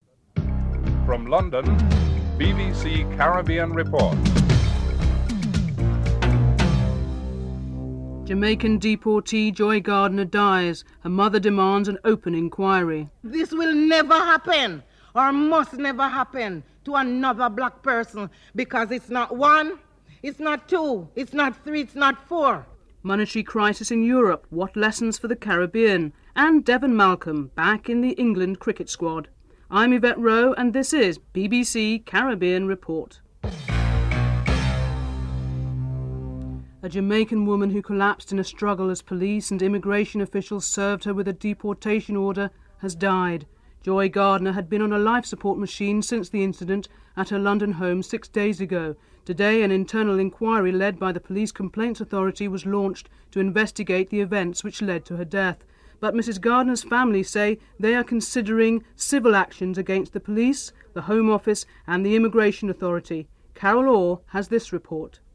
1. Headlines (00:00-00:43)
Interview with Devon Malcolm, fast bowler, England’s cricket team.